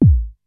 VCF BASE 1 2.wav